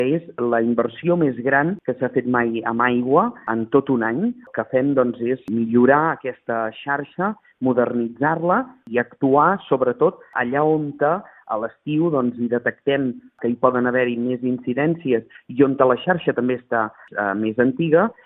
El tinent d’Alcaldia de Serveis públics, Josep Grima, ha indicat que s’invertiran 755.000 euros de fons municipals i d’aportats per la Diputació de Barcelona i la Generalitat de Catalunya, a través de l’Agència Catalana de l’Aigua.